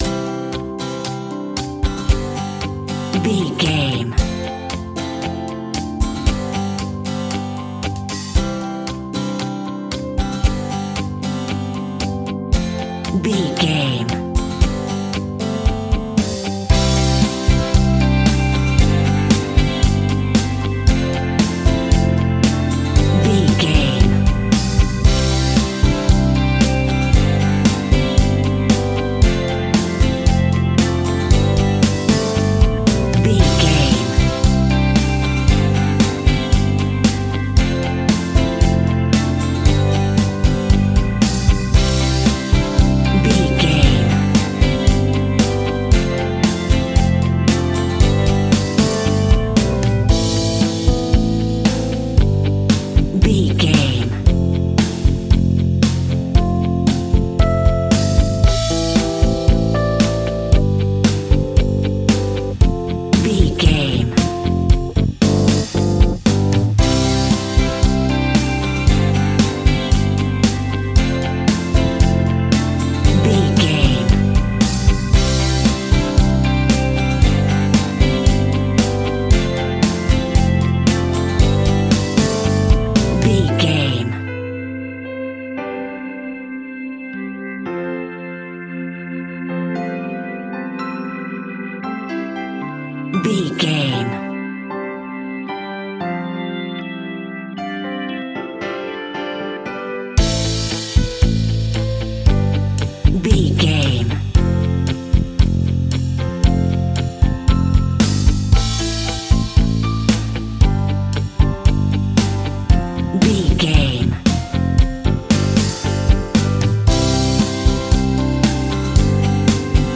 Ionian/Major
electro pop
pop rock
happy
upbeat
bright
bouncy
drums
bass guitar
electric guitar
keyboards
hammond organ
acoustic guitar
percussion